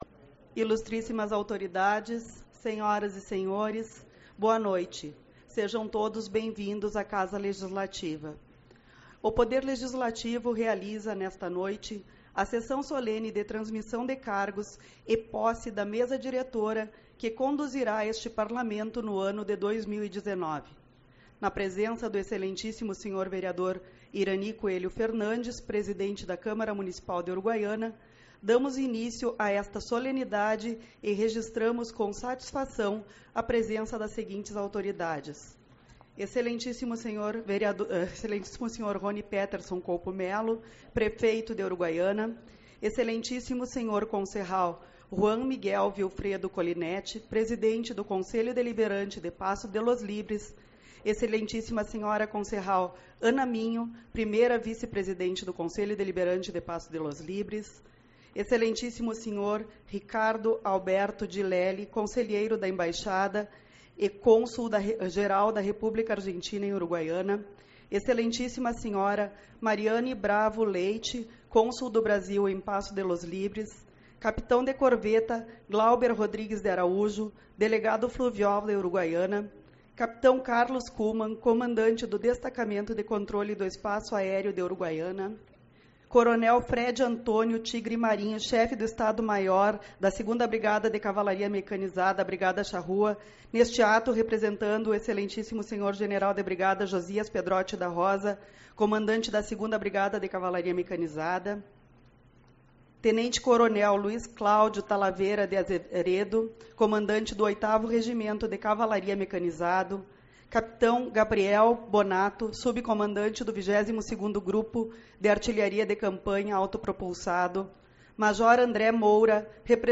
02/01 - Sessão Solene de Posse da Mesa Diretora